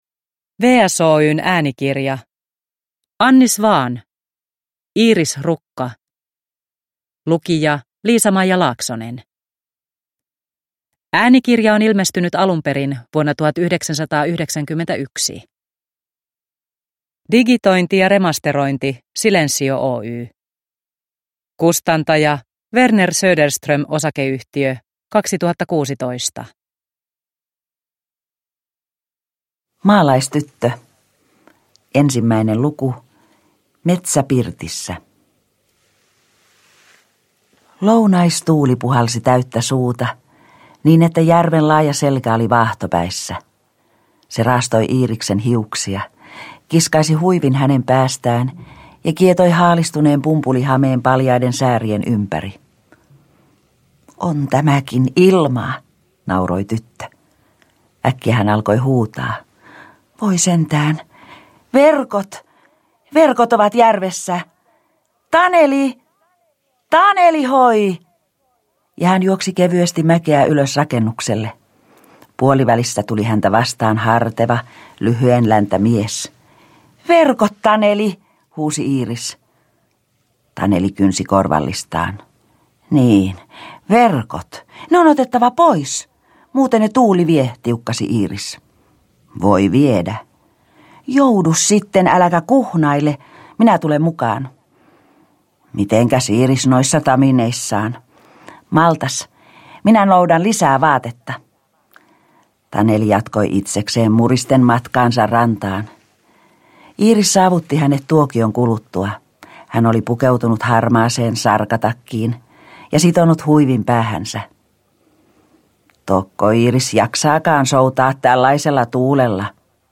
Iris rukka – Ljudbok – Laddas ner